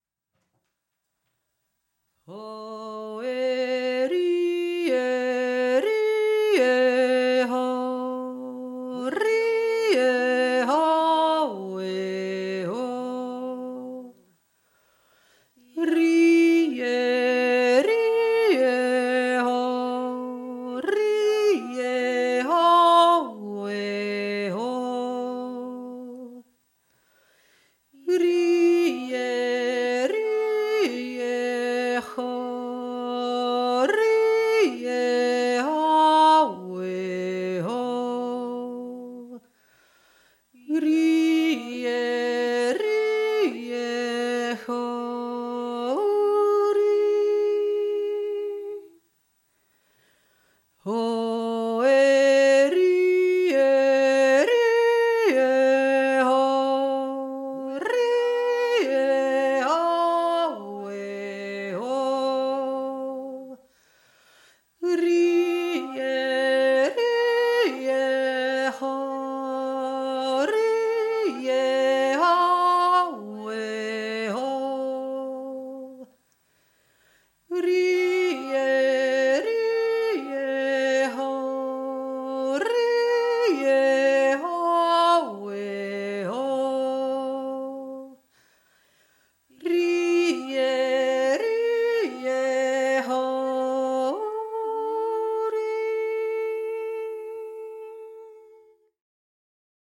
Füranond der Jodler
f-ranond-hauptstimme.mp3